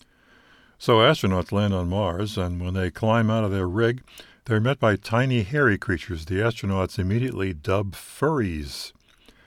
The first clip is the error and the second is the patch.
There’s no volume, rhythm, or emphasis change.
This was recorded in my quiet bedroom with that Zoom sound recorder and roll of paper towels for spacing.